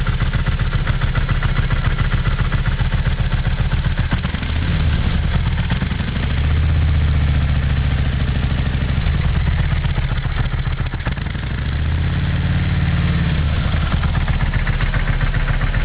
Auspuff-Sounds
(mit zugelassenen Einsätzen)